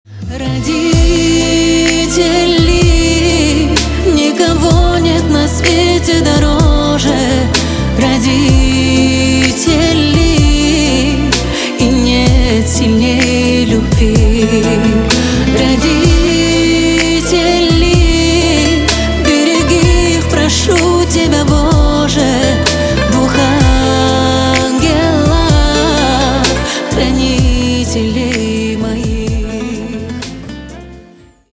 Поп Музыка # кавказские
спокойные